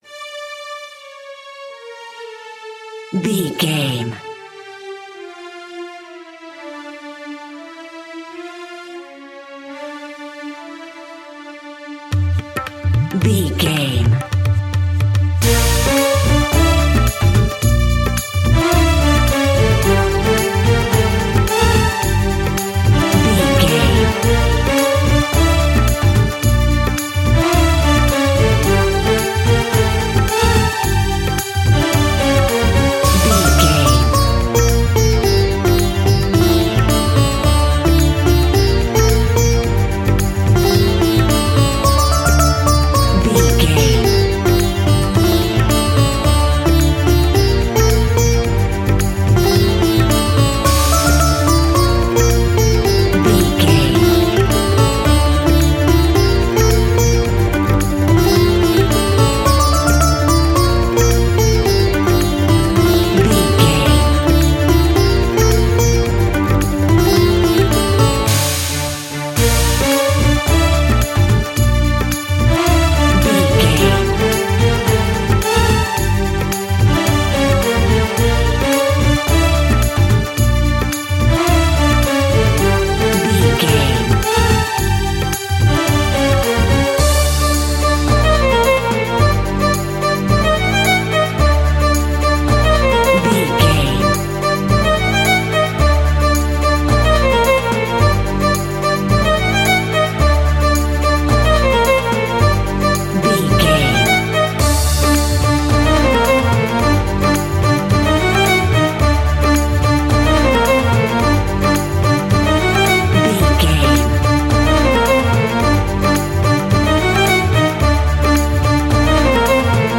Aeolian/Minor
D
World Music
percussion